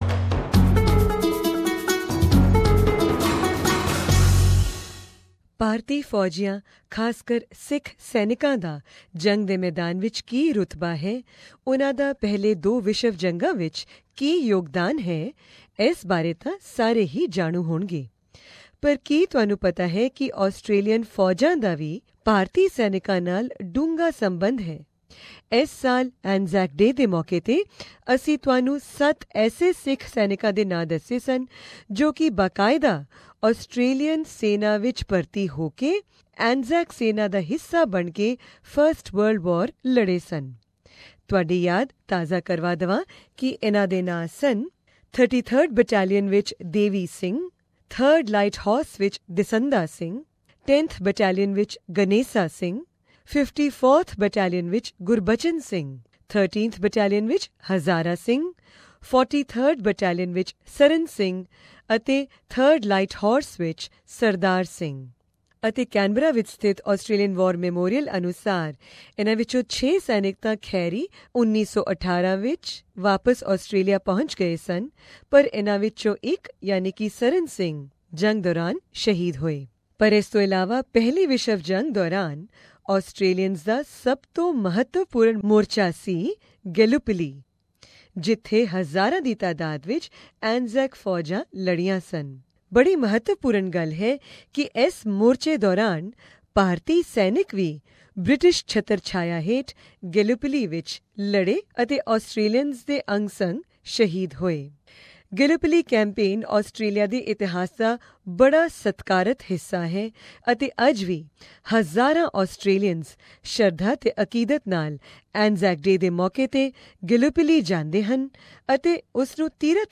This Anzac Day, we are re-publishing our interview